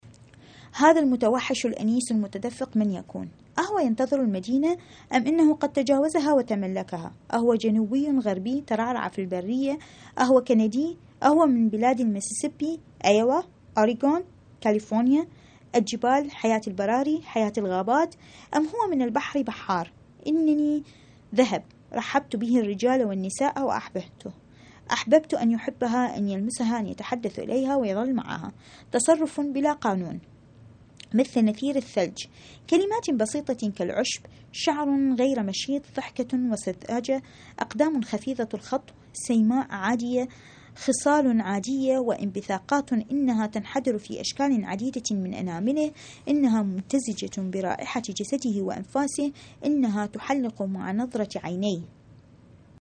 Song of Myself, Section 39 —poem read